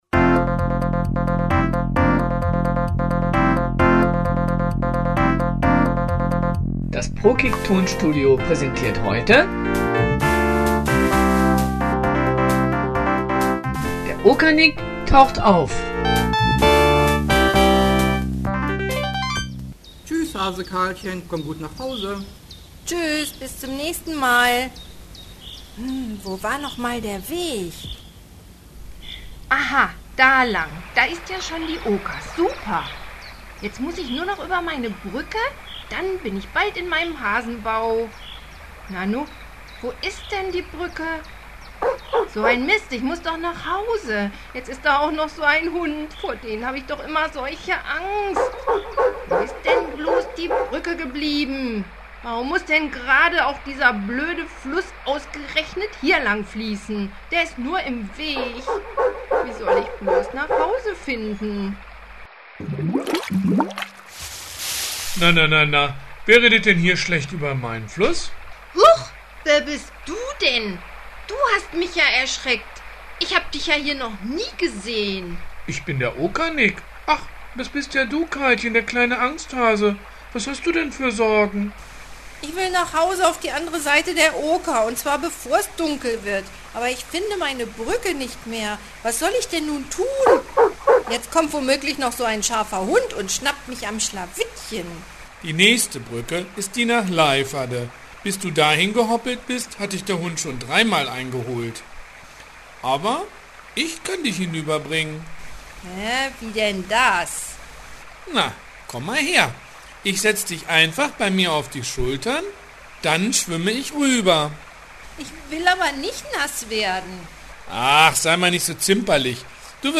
Die Geschichte von dem kleinen Hasen Karlchen und wie der Okernick auftauchte haben wir zu einem kleinen Hörstück verarbeitet, dass man sich hier